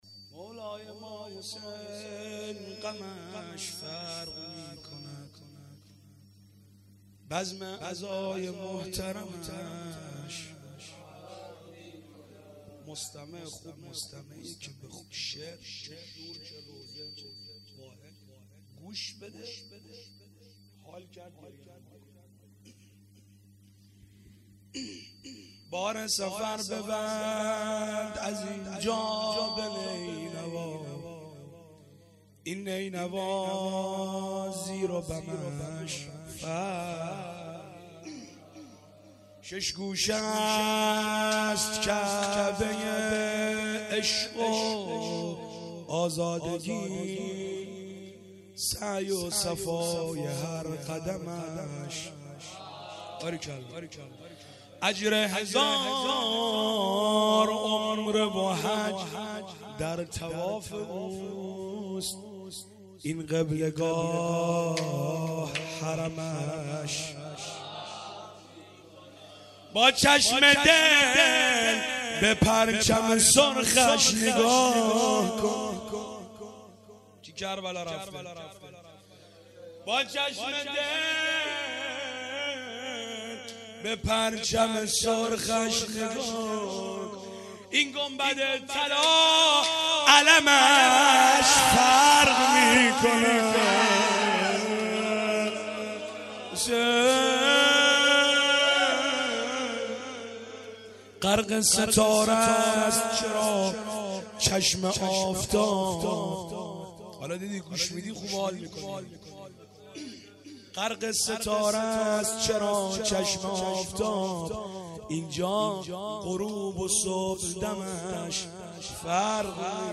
شعر خوانی